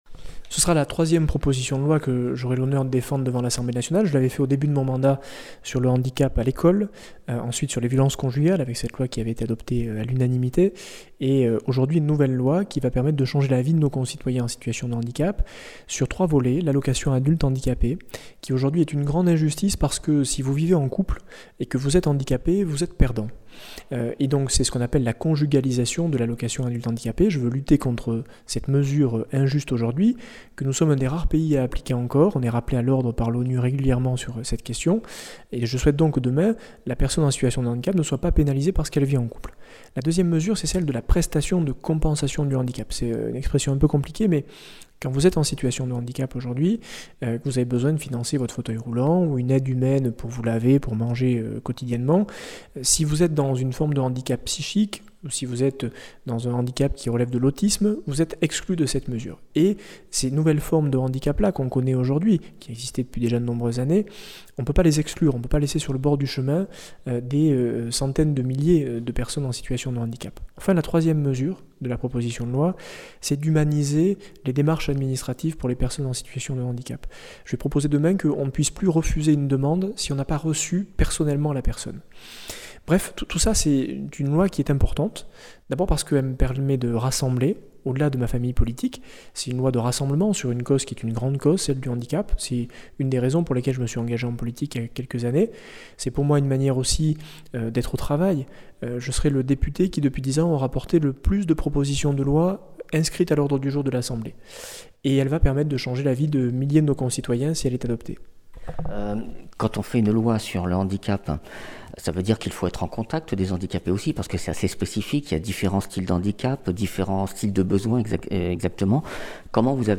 Interviews
Invité(s) : Aurélien Pradié, député LR du Lot